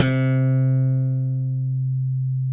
KS 32 SLAP.2.wav